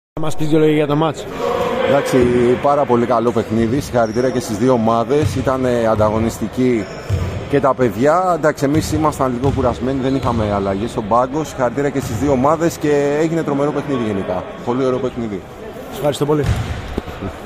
GAME INTERVIEWS:
(Παίκτης Frezyderm)